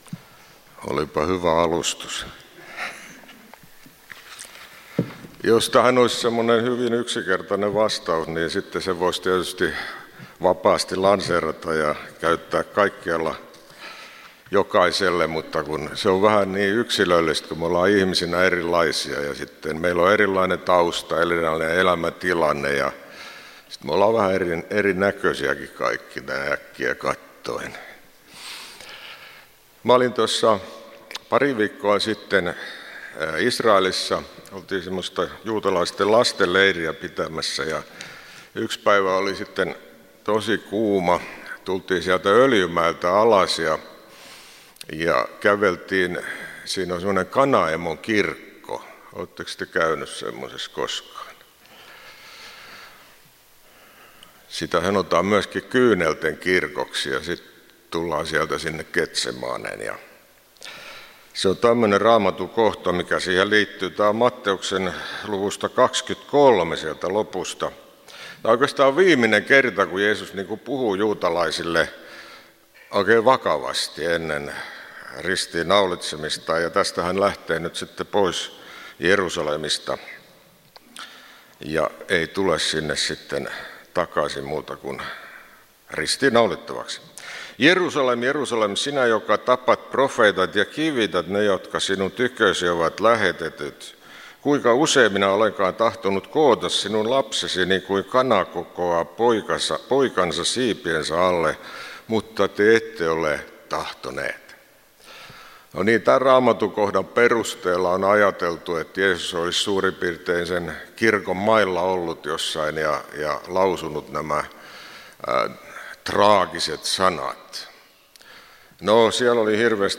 Kokoelmat: Tampereen evankeliumijuhlat 2018